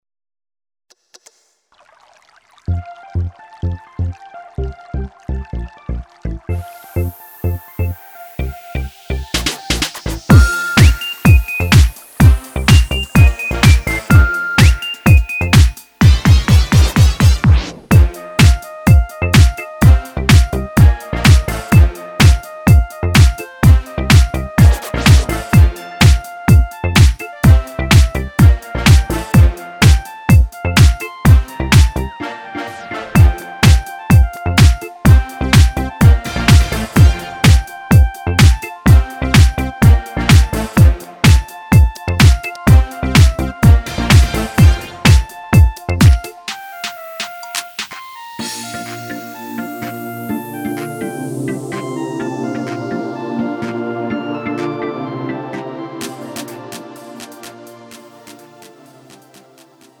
음정 여자키
장르 pop 구분 Pro MR